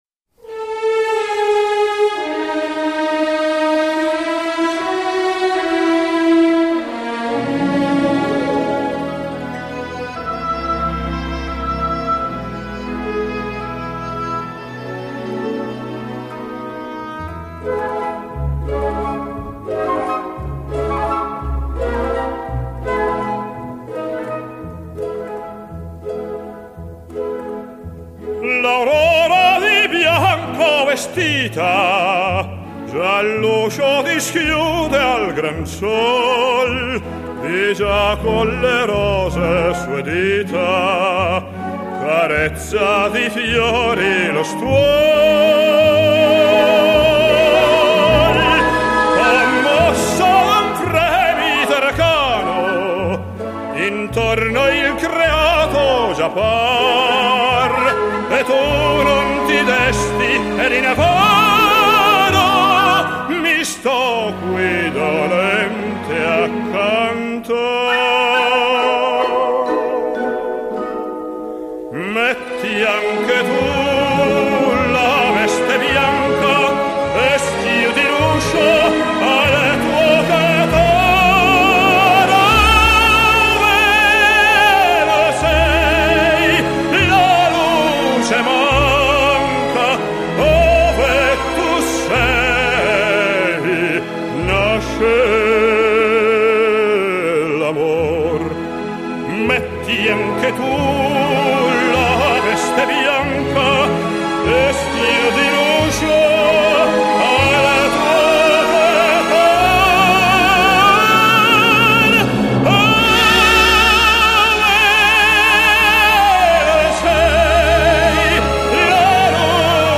浪漫意大利歌曲